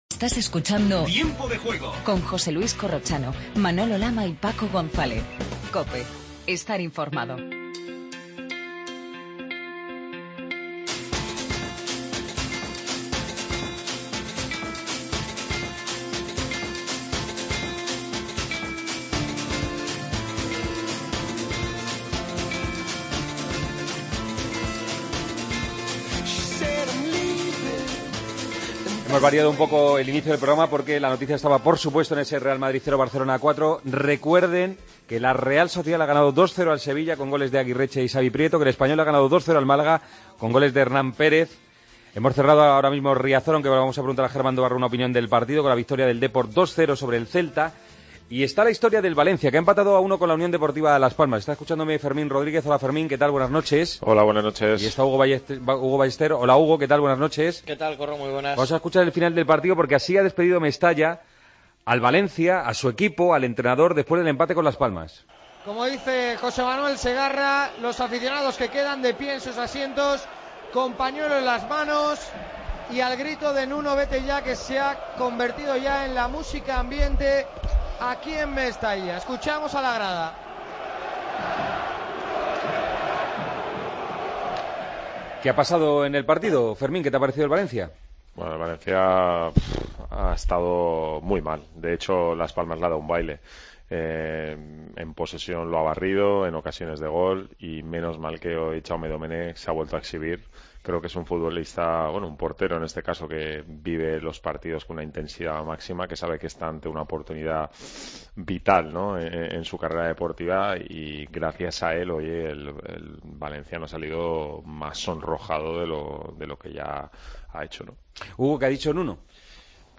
Hablamos con Xabi Prieto y Hernán Pérez....